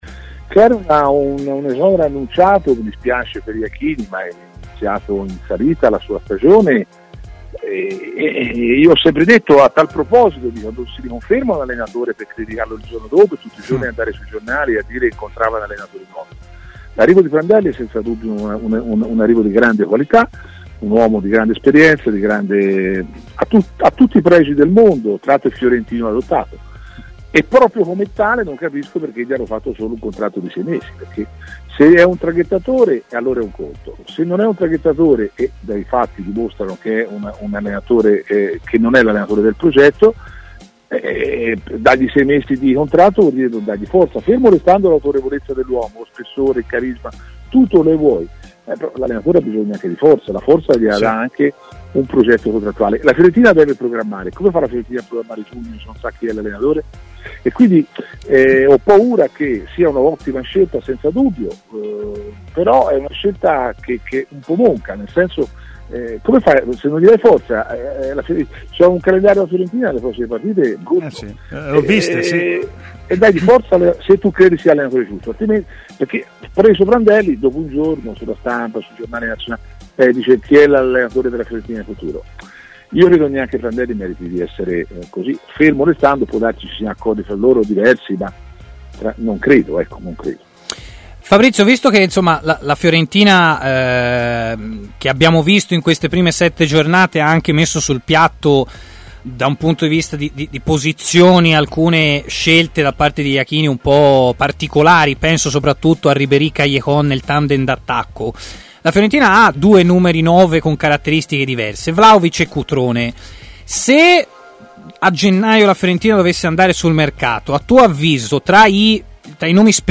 si è collegato in diretta con Stadio Aperto, trasmissione di TMW Radio